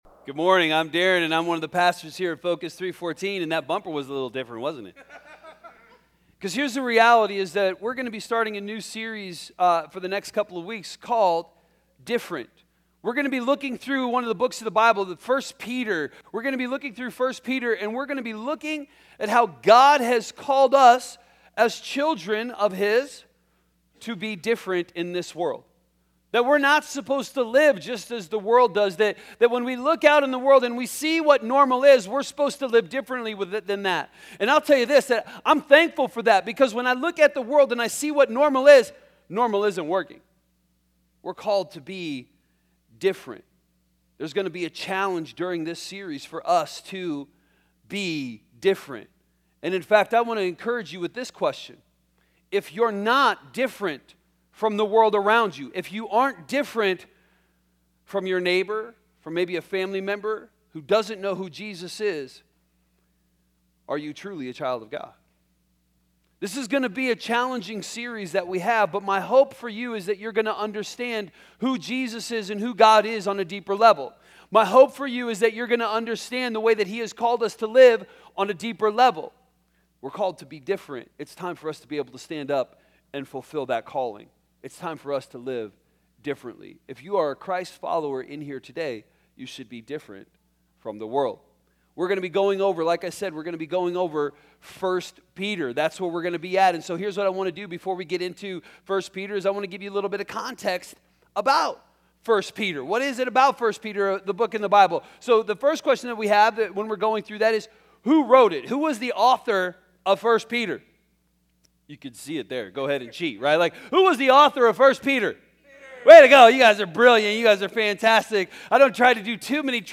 A message from the series "Different."